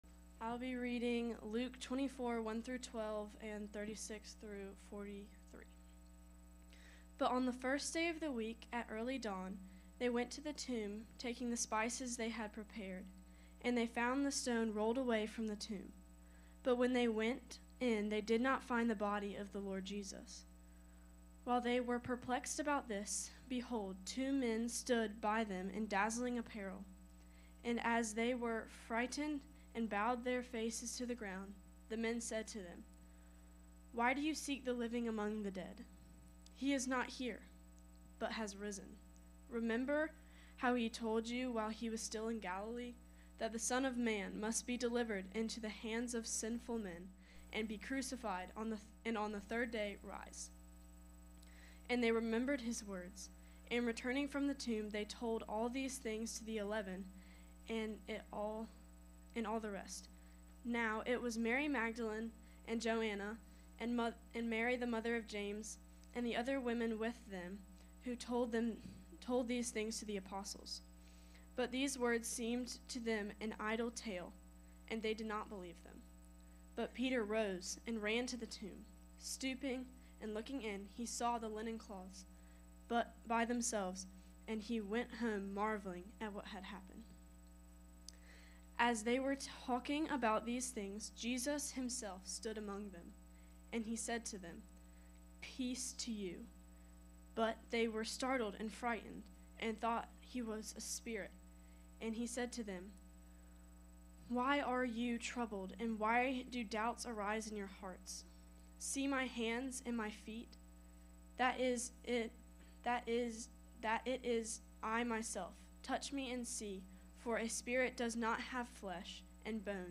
Service Type: Sunday 10am